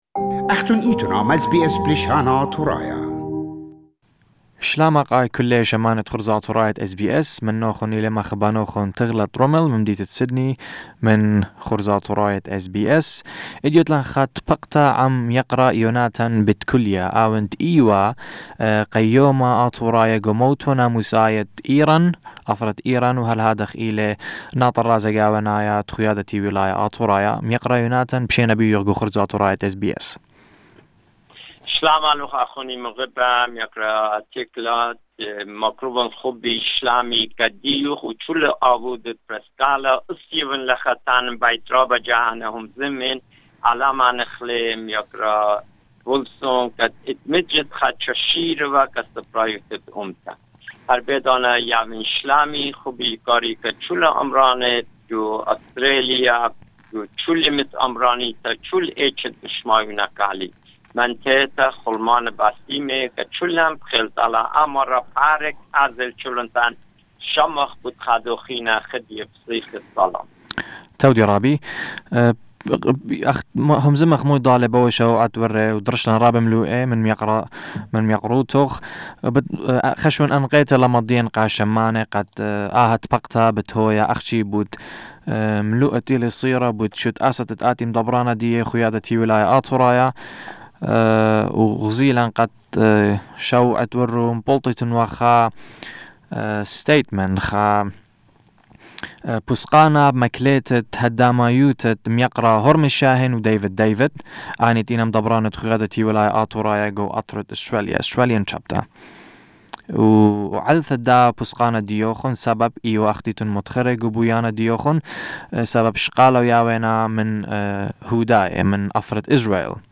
Mr. Betkolia spoke with our team through a lengthy interview to explain to the audience what his views are on the current issues between the members of the Australian chapter and himself.